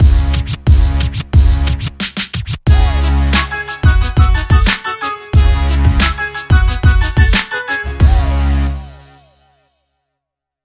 Современно